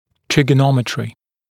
[ˌtrɪgə’nɔmɪtrɪ][ˌтригэ’номитри]тригонометрия